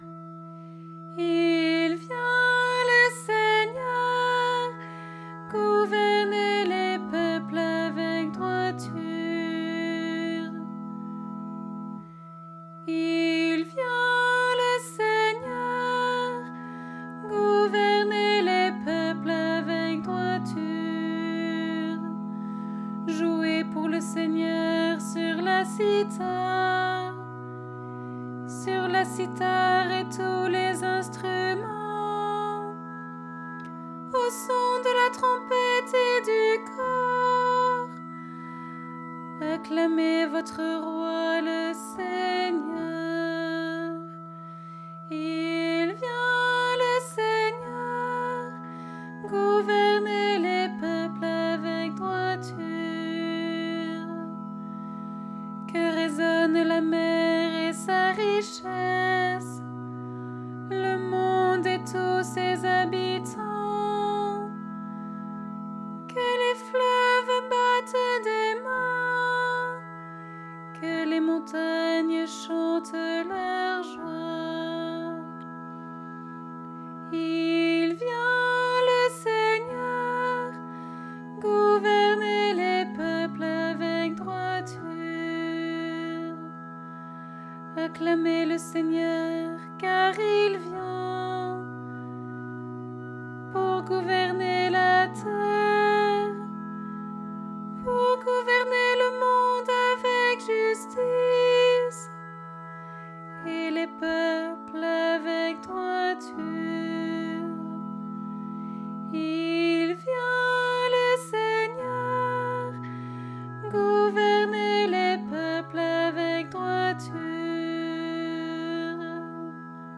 4 voix SATB